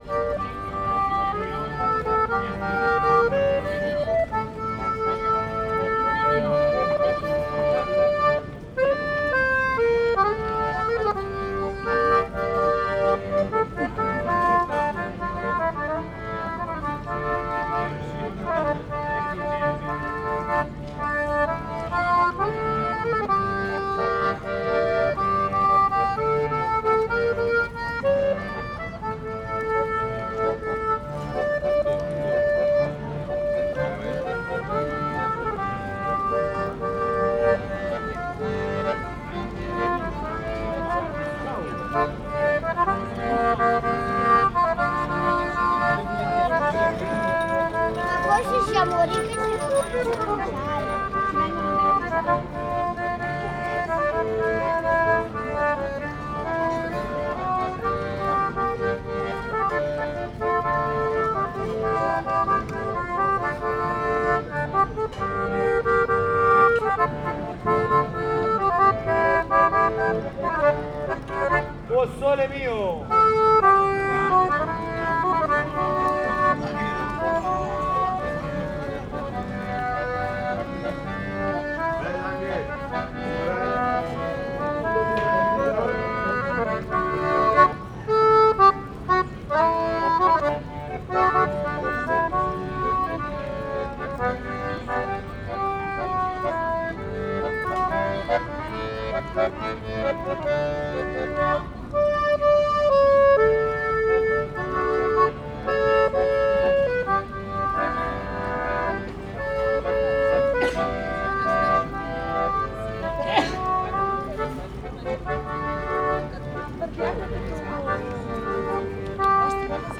harmonikas_szello_velence_omni_sds09.16.WAV